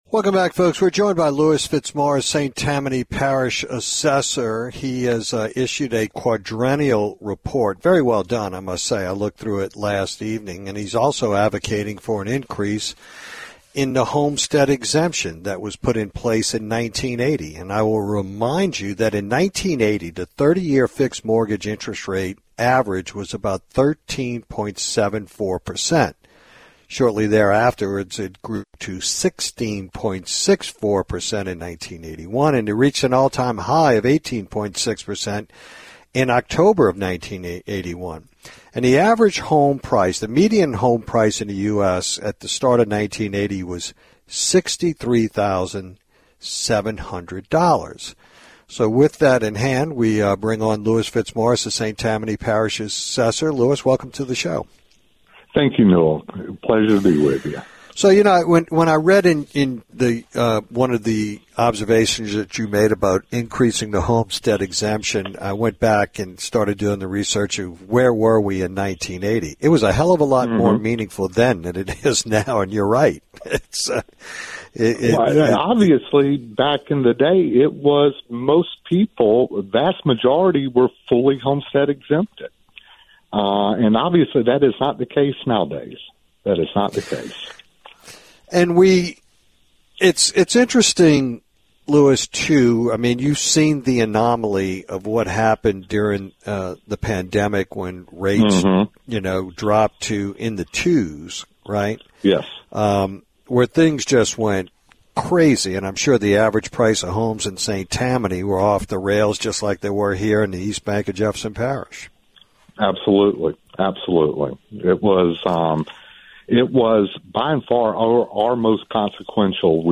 Aug 12, 2025 – Louis Fitzmorris, St. Tammany Parish Assessor, joins Newell talk about August being the official open roll period in St. Tammany Parish, the time when residents can review their property assessments and request a review, the newly released “Quadrennial Report,” which highlights how St. Tammany differs from surrounding parishes and how this affects taxpayers, and to explain why he believes it’s time to raise Louisiana’s $75,000 Homestead Exemption, which has gone unchanged for over 40 years.